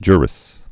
(jrĭs)